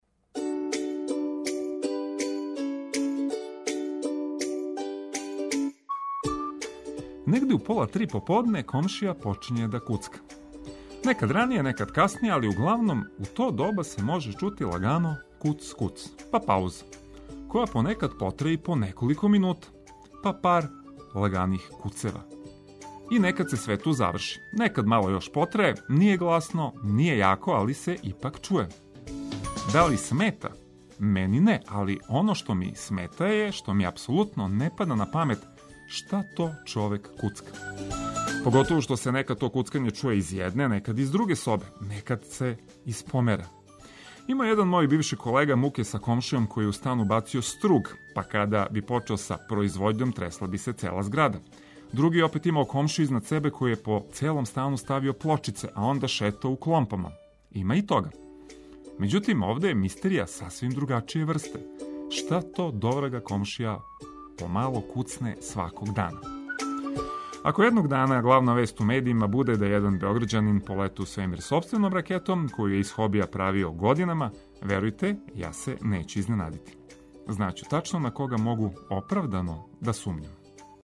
Пробудићемо вас и овог јутра музиком али и корисним информацијама.